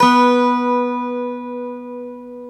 Index of /90_sSampleCDs/Club-50 - Foundations Roland/GTR_xAc 12 Str/GTR_xAc 12 Str 1
GTR X12 ST08.wav